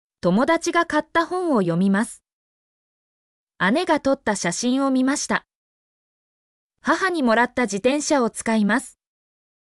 mp3-output-ttsfreedotcom-27_8NoaRyId.mp3